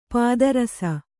♪ pāda rasa